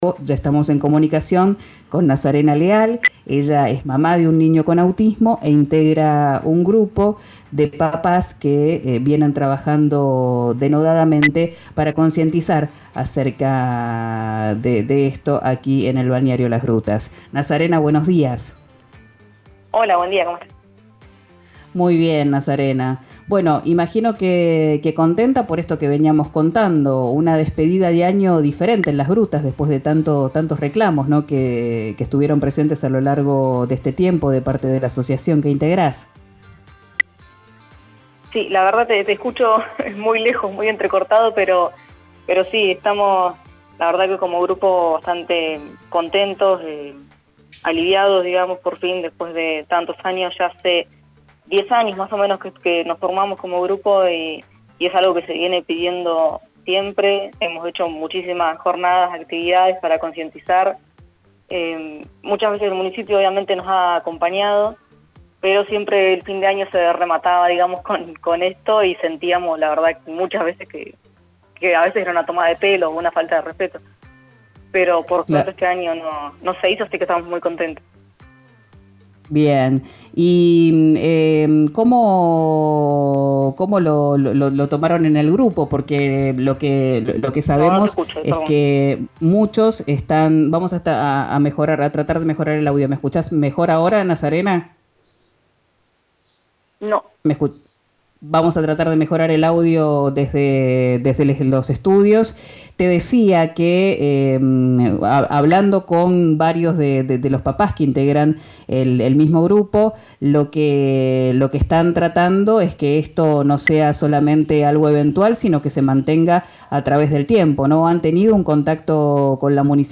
en «Quien dijo verano» por RÍO NEGRO RADIO